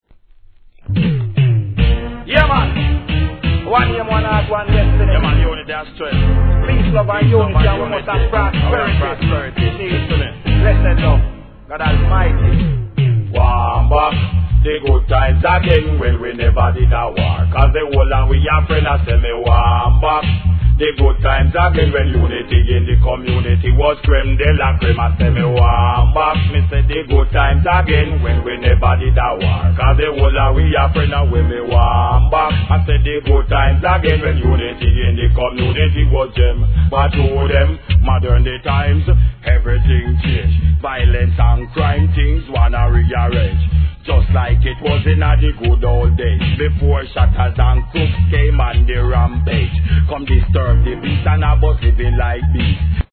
REGGAE
濁声DeeJay!